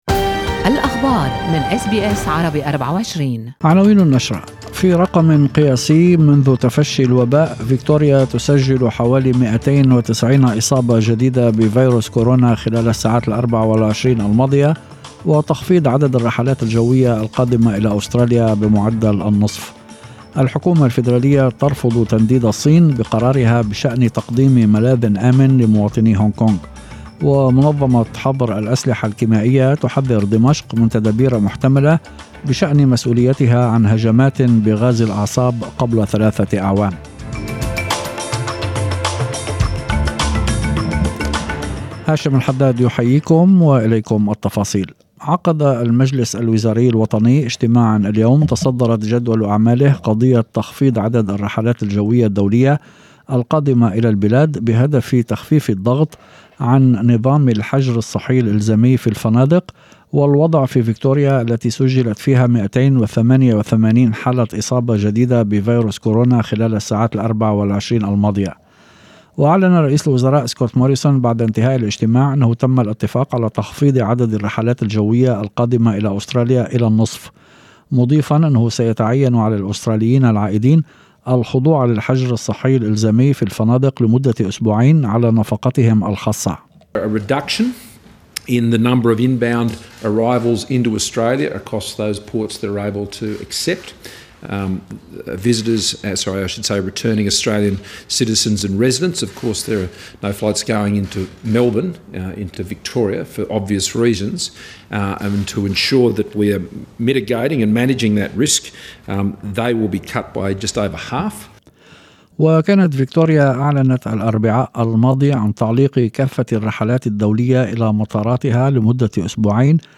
نشرة أخبار المساء 10/07/2020